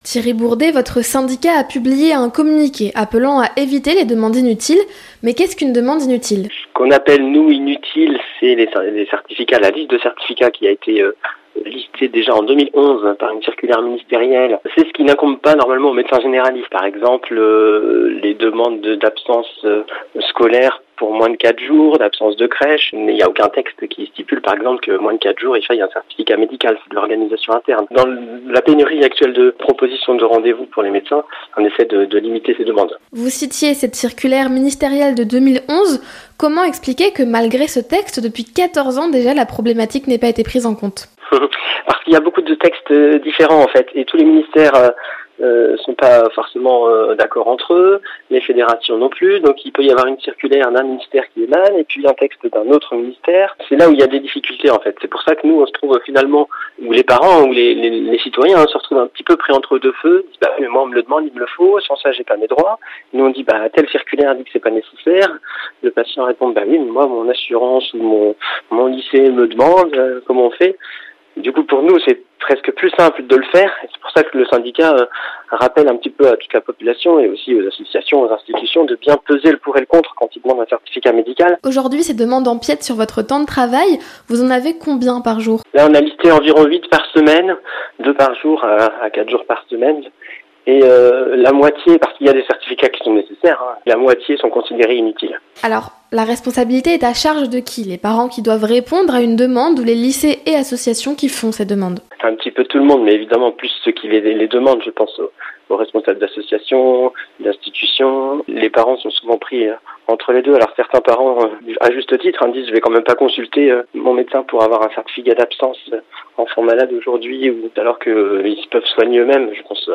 Les Reportages de NOV FM